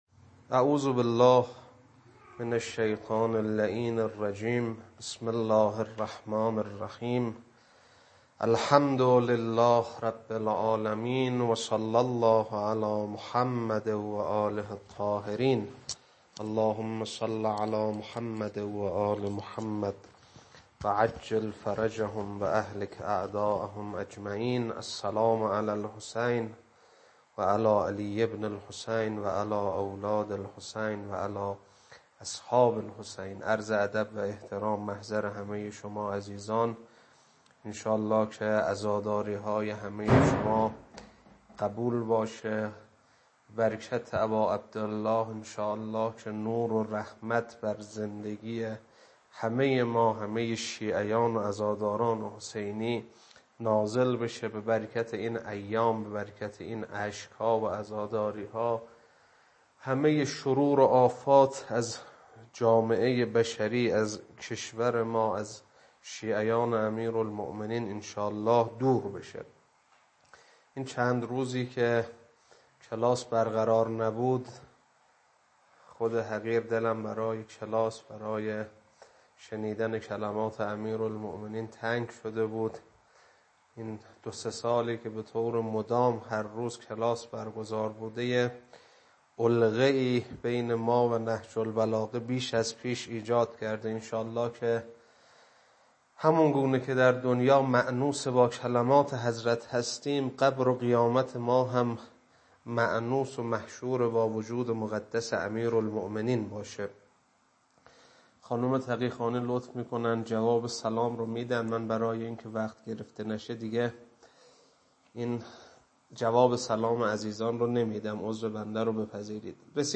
خطبه 100.mp3
خطبه-100.mp3